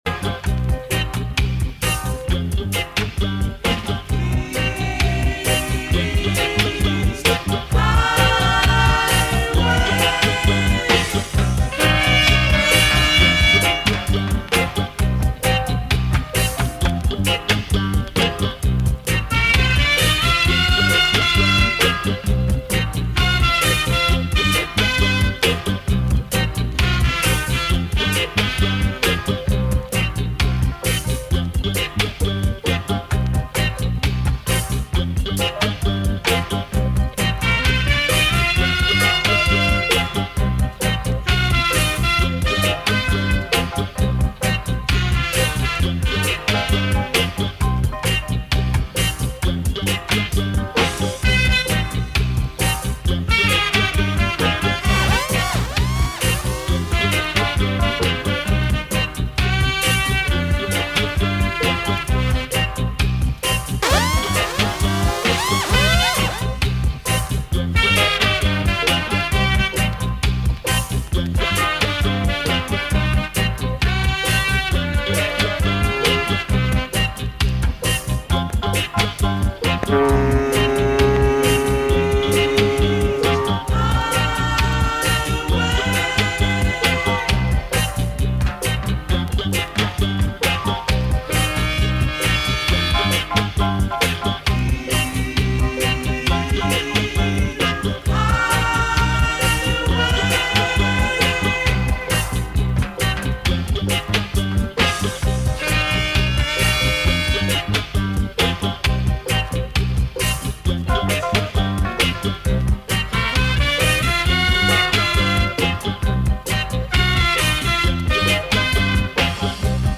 Live in Session